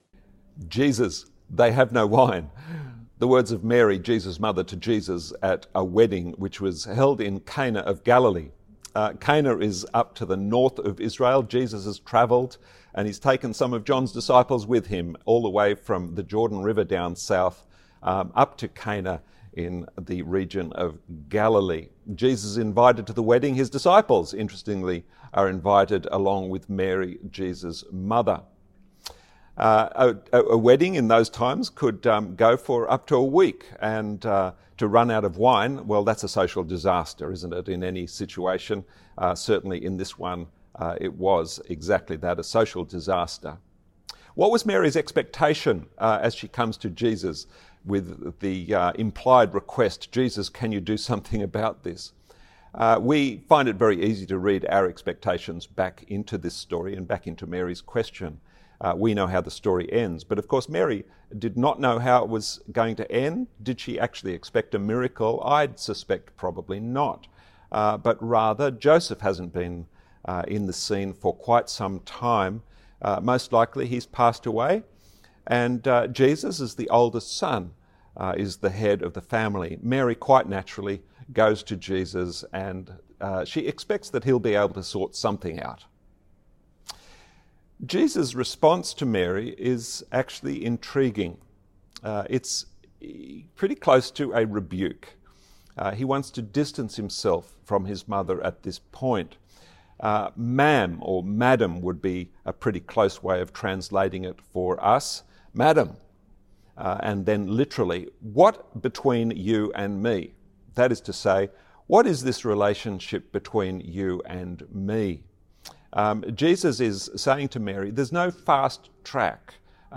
Sermons | St Johns Anglican Cathedral Parramatta
Sunday sermon